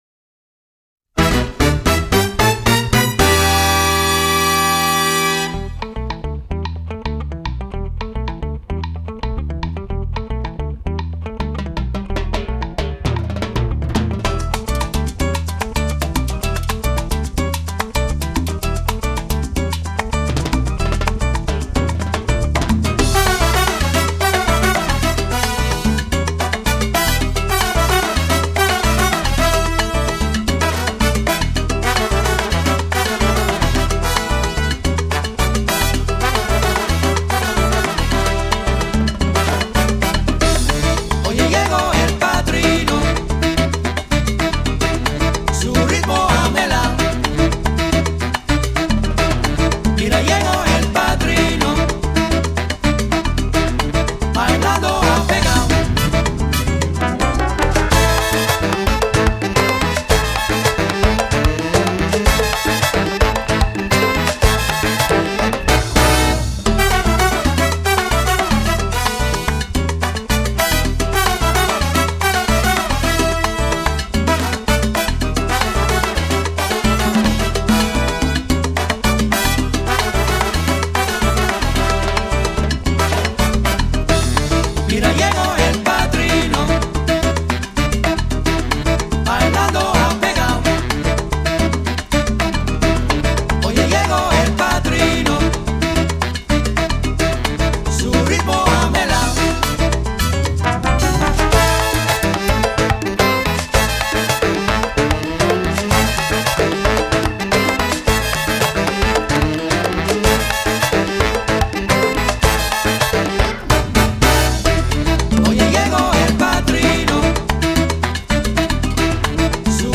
mixing in elements of funk and jazz to create a unique edge.